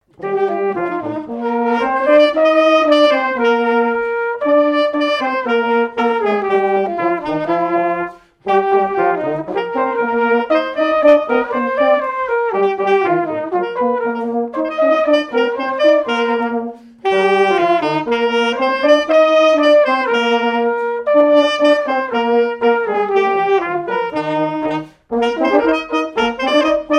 danse : quadrille : chaîne anglaise
Répertoire pour un bal et marches nuptiales
Pièce musicale inédite